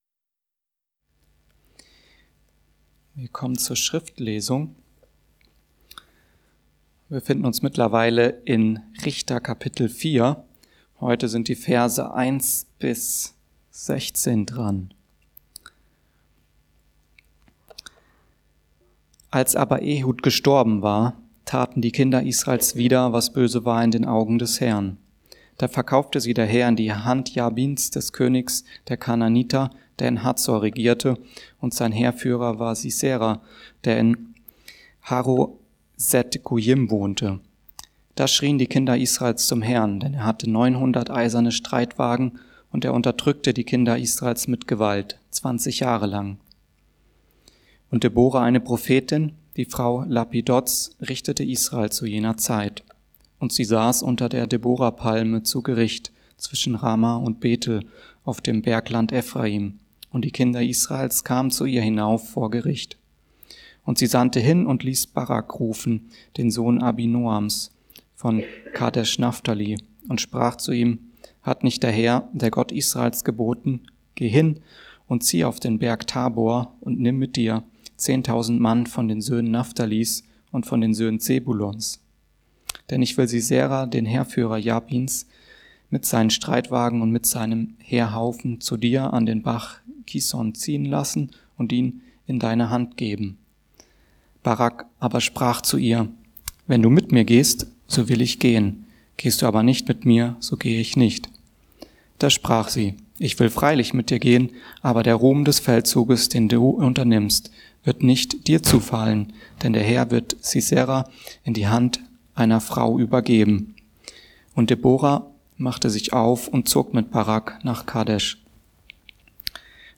Von Mitläufern und Angebern ~ Mittwochsgottesdienst Podcast